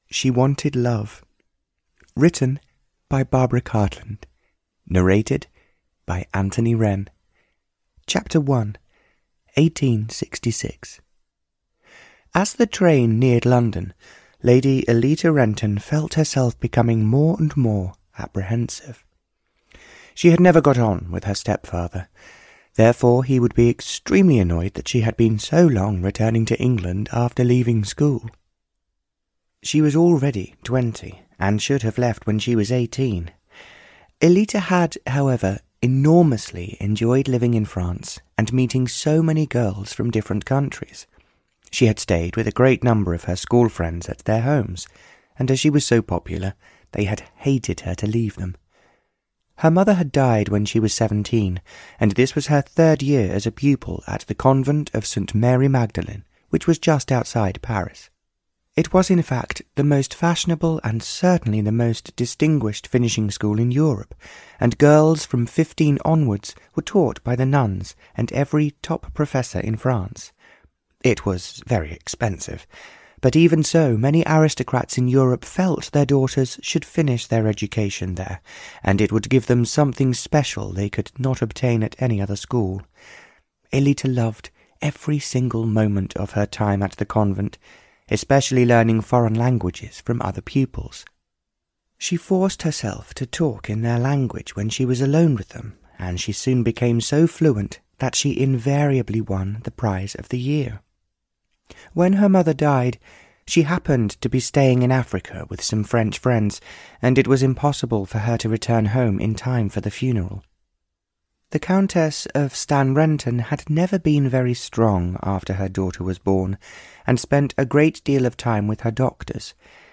She Wanted Love (Barbara Cartland's Pink Collection 103) (EN) audiokniha
Ukázka z knihy